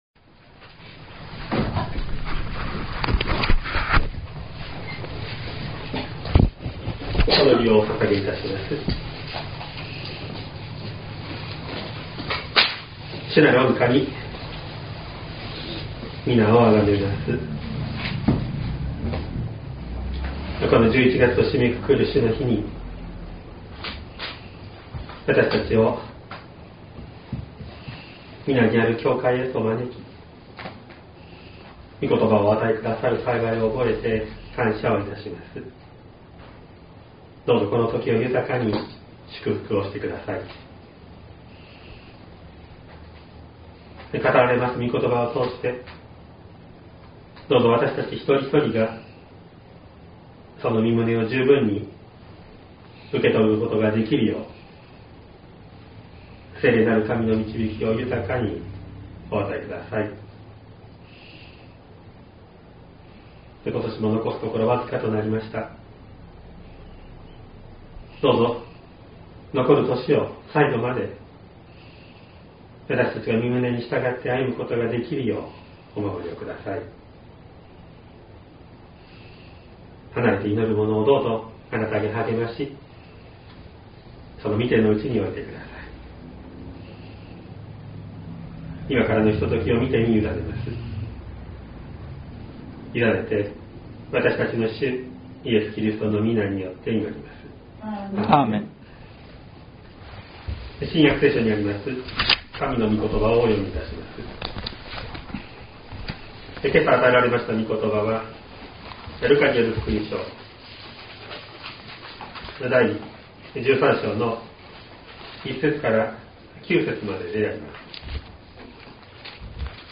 2024年11月24日朝の礼拝「悔い改めの機会」西谷教会
音声ファイル 礼拝説教を録音した音声ファイルを公開しています。